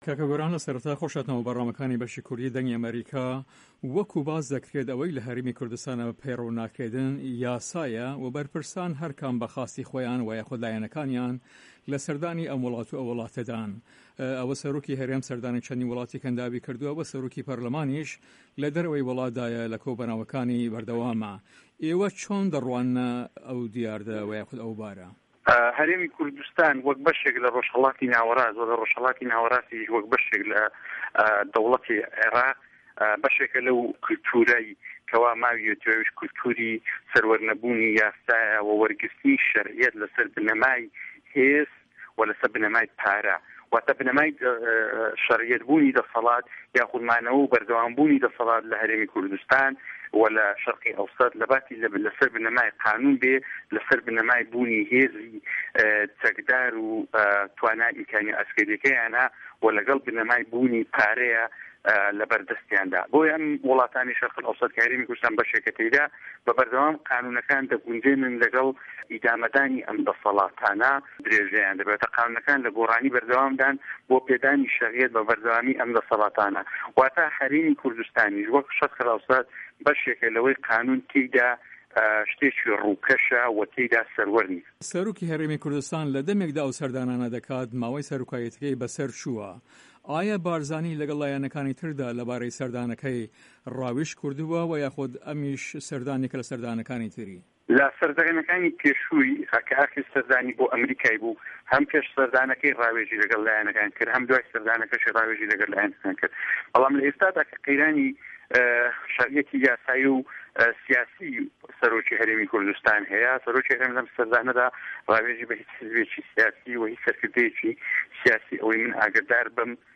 گۆران ئازاد ئەندام پەرلەمانی هەرێمی کوردستان لەسەر لیستی یەکێتی نیشتمانی کوردستان لە هەڤپەیڤینێکدا لەگەڵ بەشی کوردی دەنگی ئەمەریکا سەبارەت بەو رێز نەگرتنە لە یاسا دەڵێت" هەرێمی کوردستان وەک بەشێک لە رۆژهەڵاتی ناوەراست پەروەردەی کەلتوری نەبوونی یاسایە، کە لە جیاتی ئەوەی لەسەر بنەمای یاسا بێت لەسەر بنەمای هێزە چەکداری و داراییەکەیدایە، لەبەر ئەوە یاساکان لە گۆڕانی بەردەوام دان بۆ پێدانی شەرعیەت بە بەردەوامی ئەم دەسەڵاتانە.